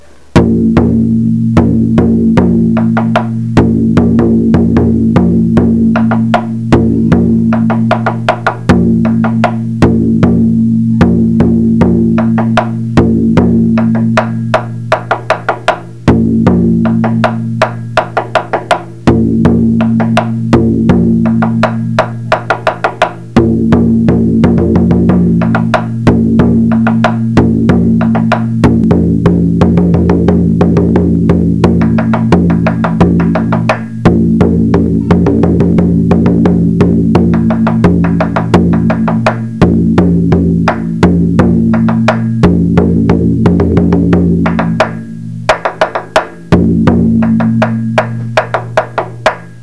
The Rolling Thunder Taiko Resource is dedicated to serving the performers, music and fans of Japanese art of Taiko drumming.
Listen to Sansa Daiko, each section played once through.